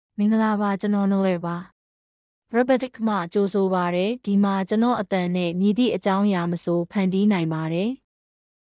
Nilar — Female Burmese AI voice
Voice sample
Female
Nilar delivers clear pronunciation with authentic Myanmar Burmese intonation, making your content sound professionally produced.